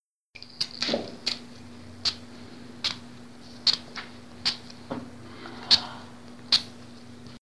Lanzando y recogiendo una pelota
Grabación sonora que capta el sonido de alguien que lanza al aire y recoge una pelota entre sus manos.
Sonidos: Acciones humanas